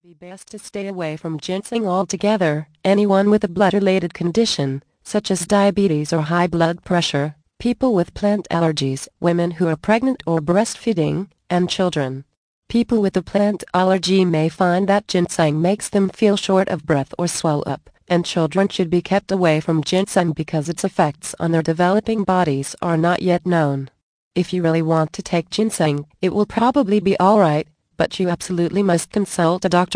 The Magic of Sleep audio book Vol. 3 of 14, 61 min.